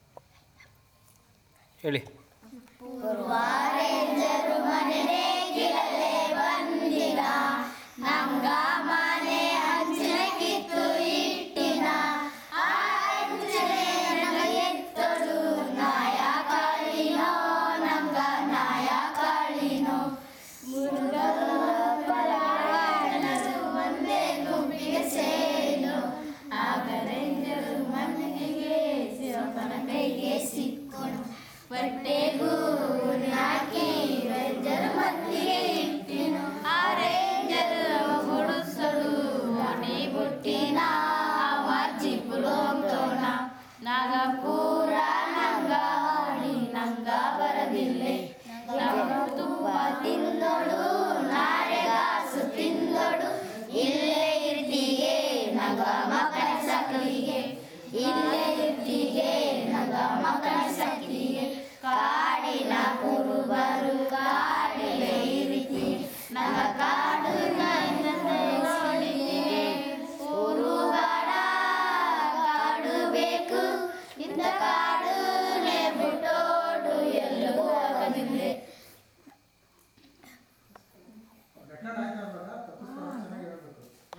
Performance of a folk song expressing their desire to reclaim their lost land